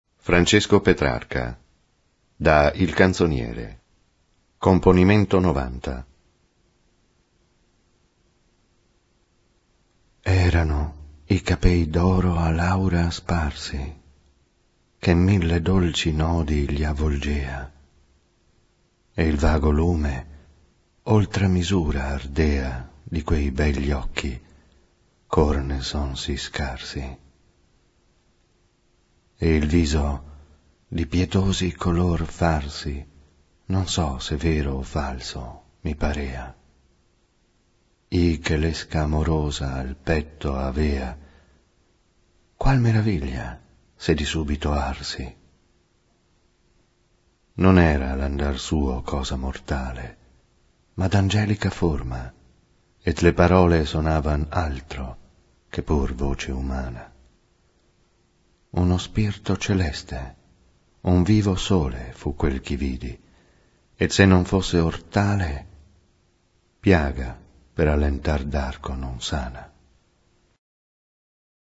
recited in Italian